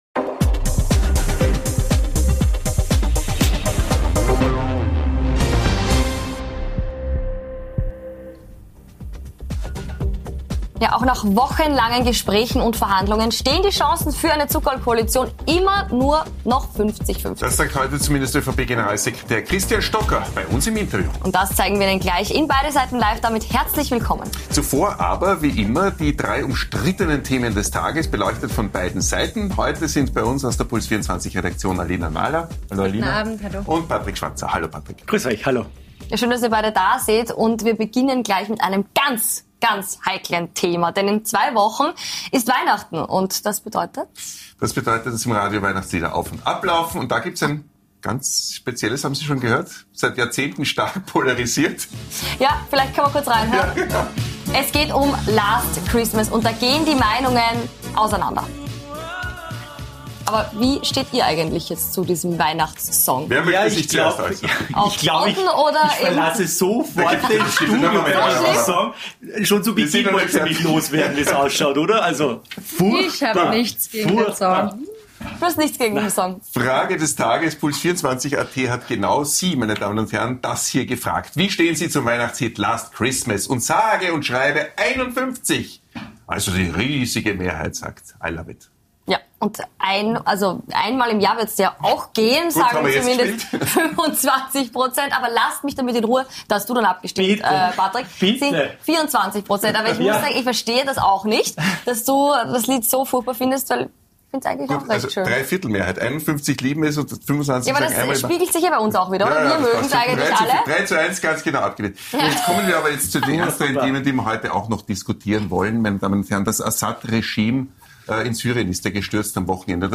Beschreibung vor 1 Jahr Wir beleuchten heute beide Seiten zu folgenden Themen: Werden Syrerinnen und Syrer in Österreich nun abgeschoben? Was plant die Dreierkoalition gegen den Ärztemangel zu tun? Nachgefragt haben wir heute bei ÖVP Generalsekretär und Verhandler Christian Stocker wie denn die Regierungsverhandlungen laufen.